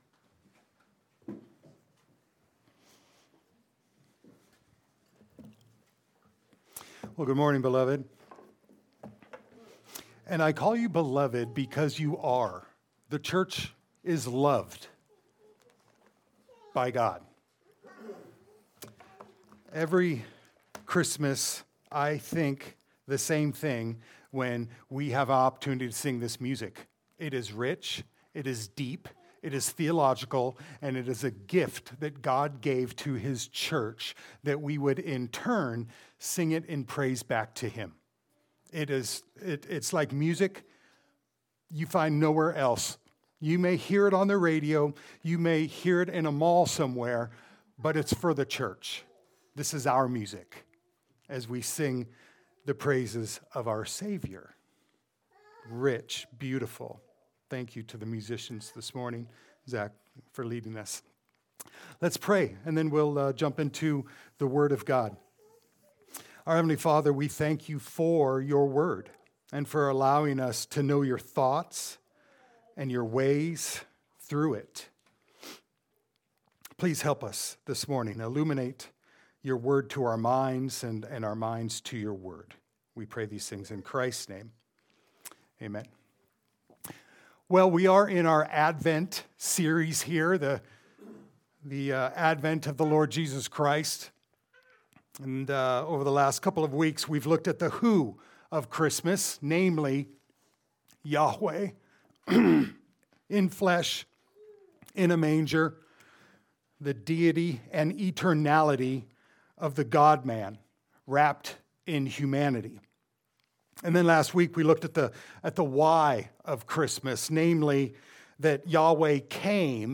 Christmas Advent Passage: Luke 2:1-21 Service Type: Sunday Service « “Christmas Advent Series